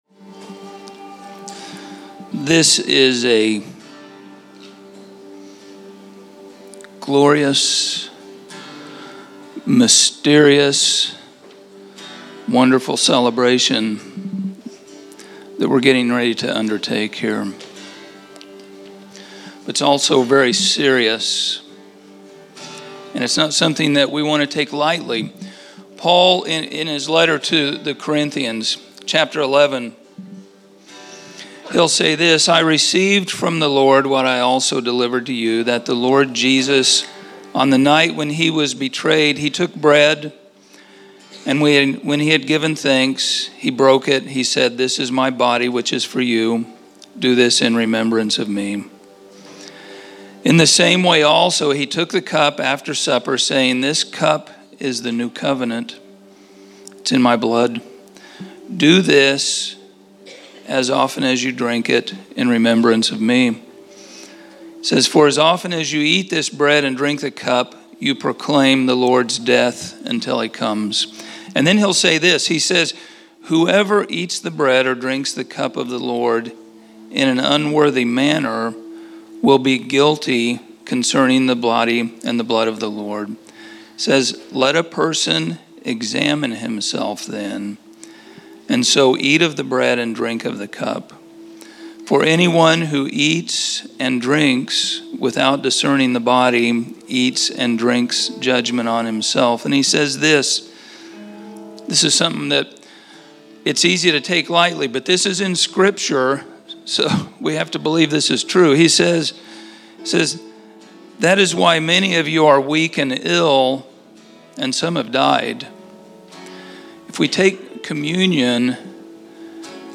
Category: Elder Moment      |      Location: El Dorado